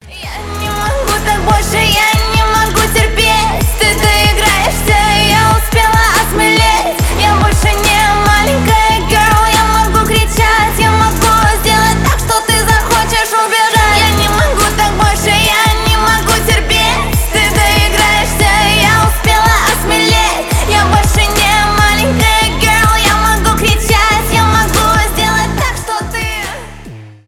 2022 » Русские » Поп Скачать припев